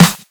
• Loud Snare One Shot F Key 321.wav
Royality free snare drum sample tuned to the F note. Loudest frequency: 2072Hz
loud-snare-one-shot-f-key-321-qWx.wav